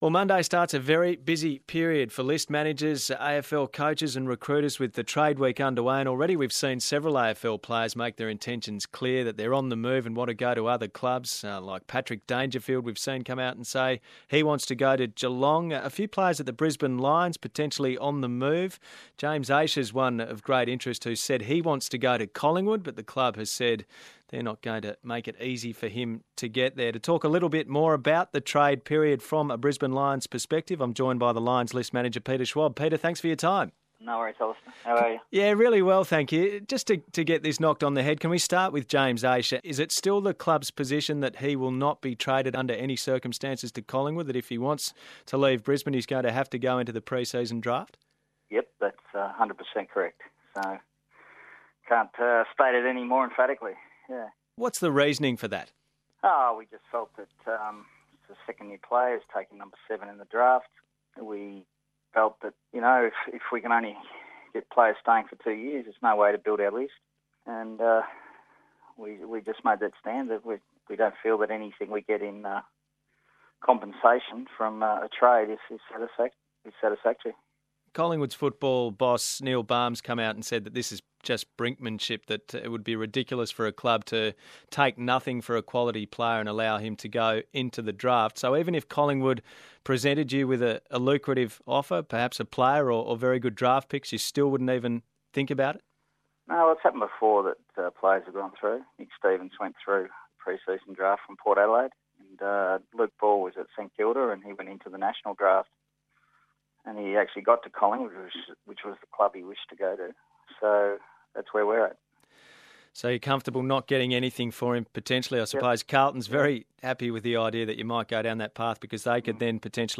Peter Schwab speaks to ABC Grandstand ahead of the Trade Period.